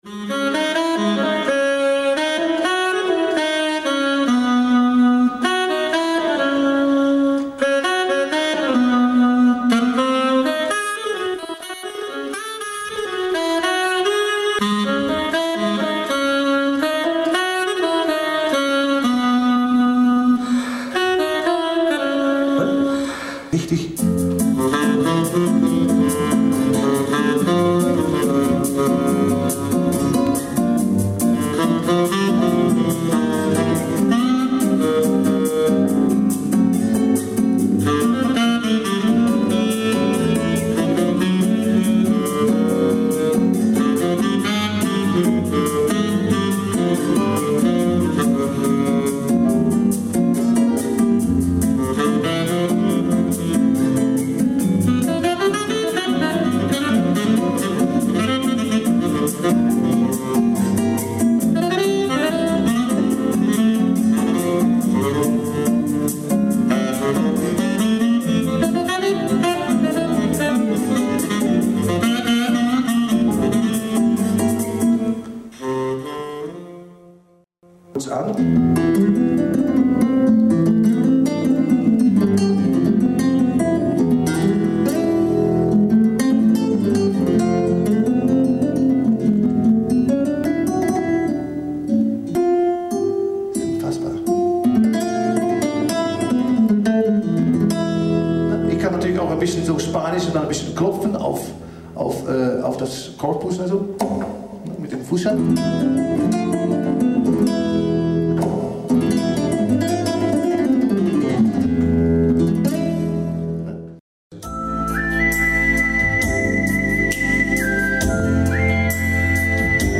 Demo in Innsbruck
Op 11 september werd de Tyros 2 in Innsbruck gedemonstreerd aan de top-dealers van Europa.
De opnamen zijn wel analoog opgenomen, dus de kwaliteit van het geluid is matig. Toch is de goede kwaliteit van de sound van de Tyros 2 duidelijk te horen.
Tyros Live Demo Part 1.mp3